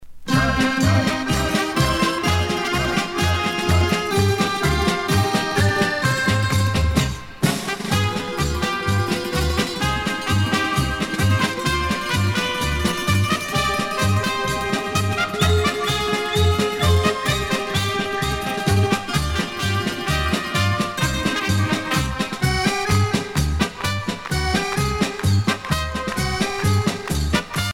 danse : kazatchok
Pièce musicale éditée